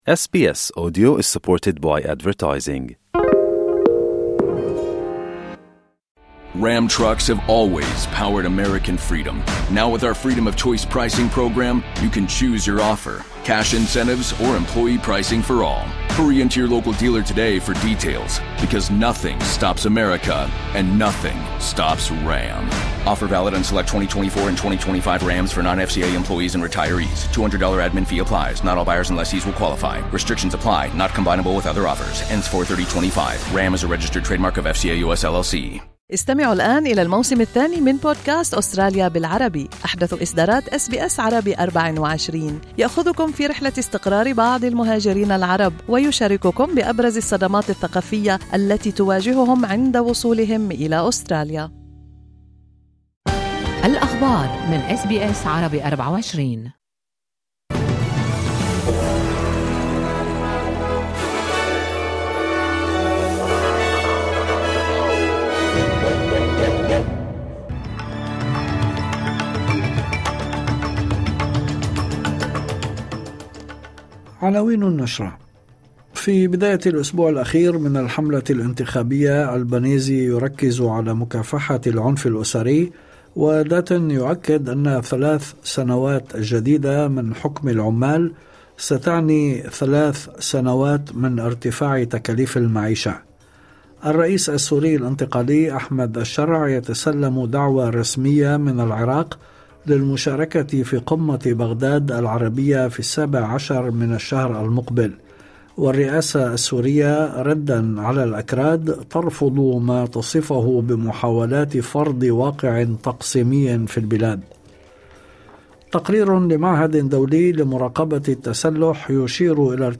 نشرة أخبار المساء 28/04/2025